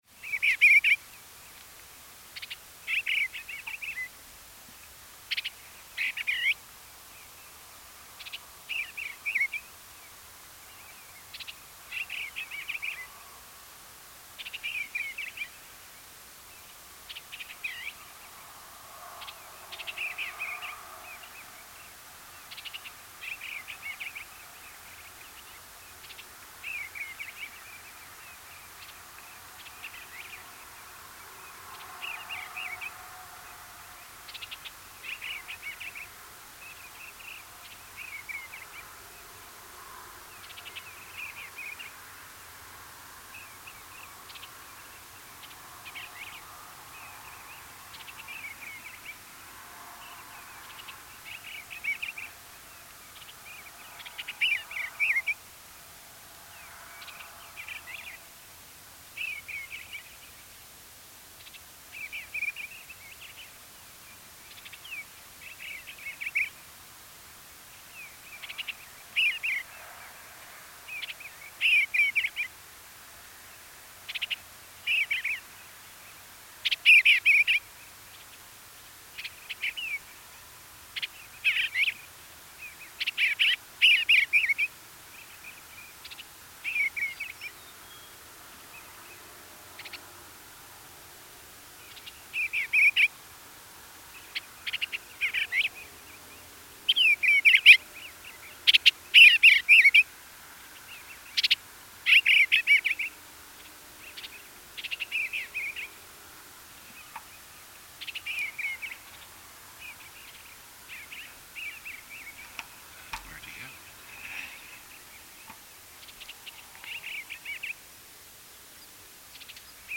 Adult male Eastern Bluebird singing while flying predawn
Habitat: Warm season grass field, forest replanting, agricultural fields.. Specific location: Antietam National Battlefield at the old Wilson Farm House at the intersections of Smoketown Road and The Cornfield Avenue., Washington Co., Maryland.
Eastern-Blue-Call.mp3